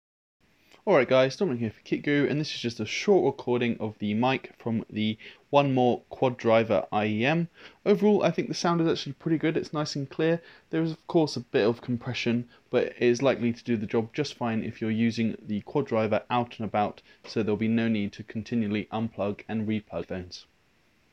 Mic
Lastly, as the Quad Driver can be used to conduct phone calls when plugged in, it is worth briefly touching on the sound quality of the in-built mic.
There is some compression, but compared to a phone's integrated mic the Quad Driver holds its own just fine.
mic-test.m4a